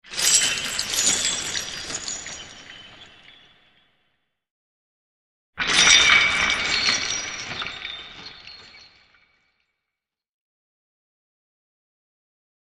Звуки цепи
Звон кандалов на ногах заключенного